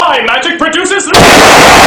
Download Ear R**e sound button
ear-rape.mp3